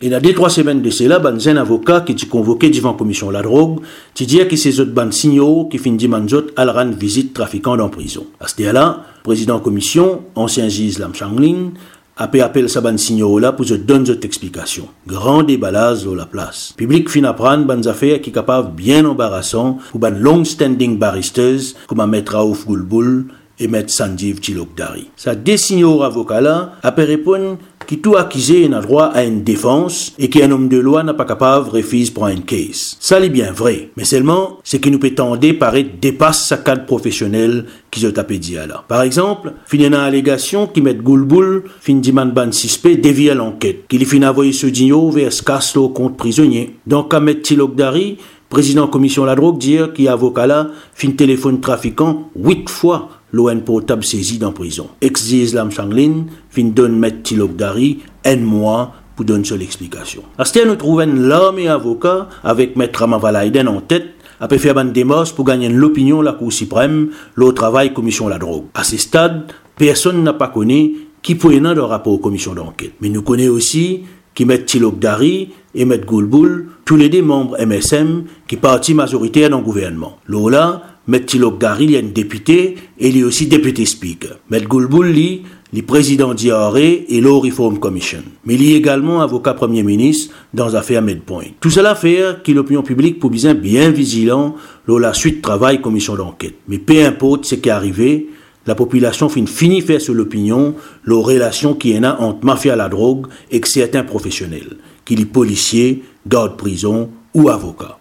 Les deux hommes de loi sont des membres du MSM. Ecoutez les commentaires de Jérôme Boulle.